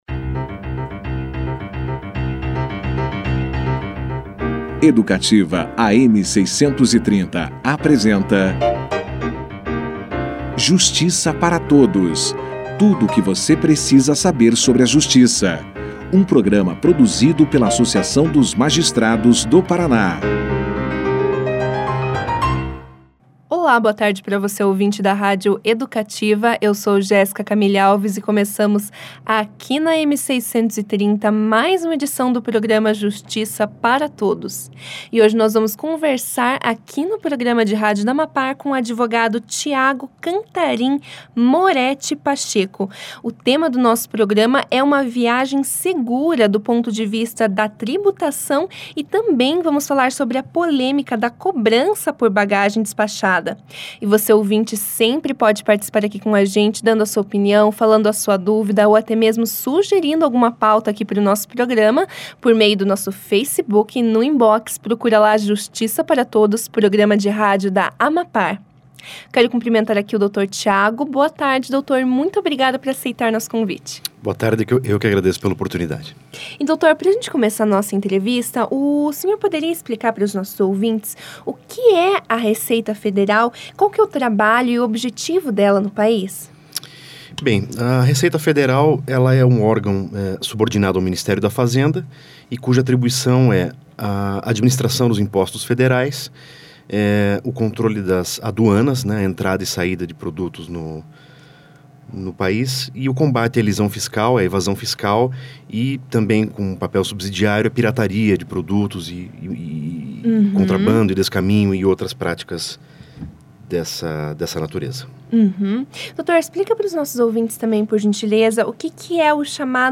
O advogado explica, já no início da entrevista, o trabalho realizado pela Receita Federal, além de falar sobre o chamado e-DBV e sua finalidade.